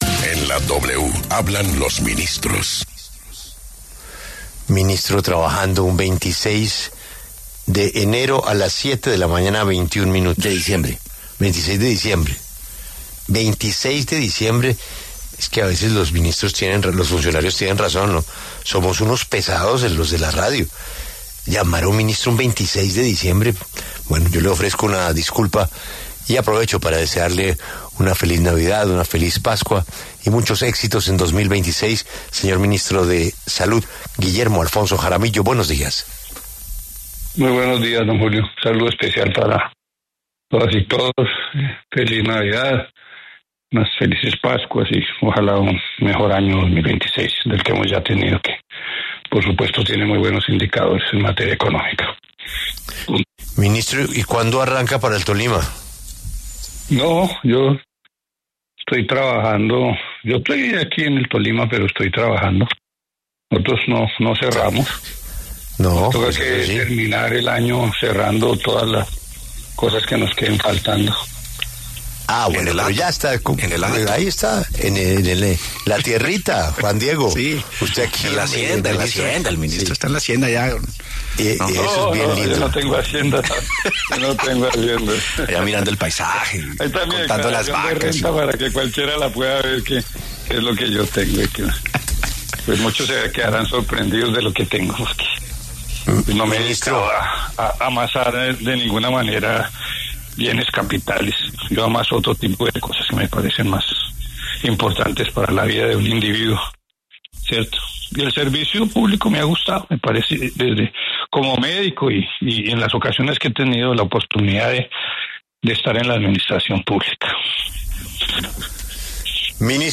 El ministro de Salud, Guillermo Alfonso Jaramillo, habló en una entrevista con La W sobre las recientes tensiones con las agremiaciones ANDI y ACEMI, en torno a la Unidad de Pago por Capitación (UPC).